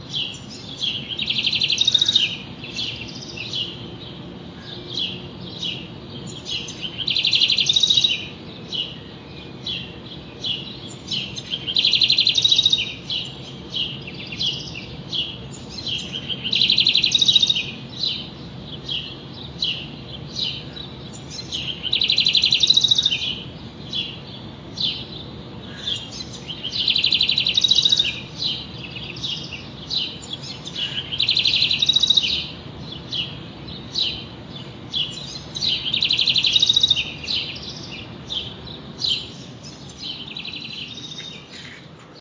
grabación en la ventana, recién